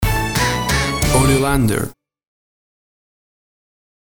WAV Sample Rate 16-Bit Stereo, 44.1 kHz
Tempo (BPM) 175